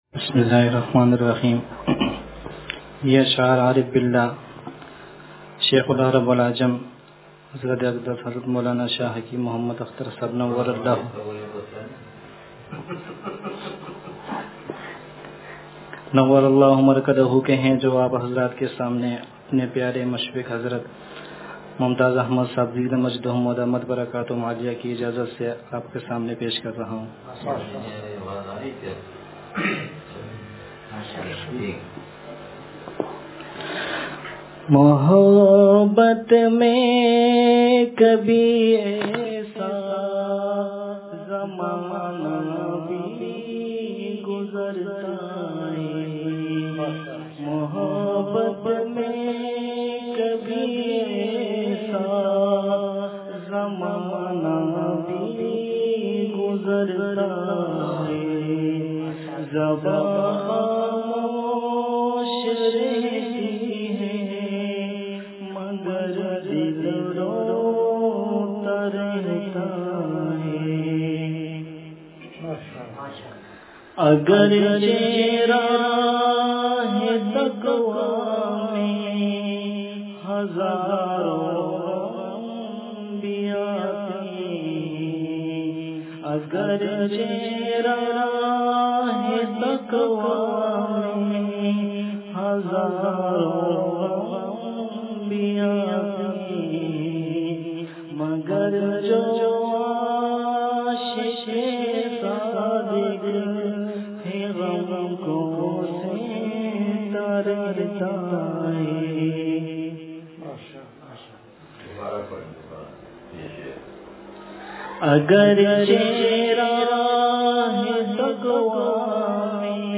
جلسئہ قربِ محبت – بیان بدھ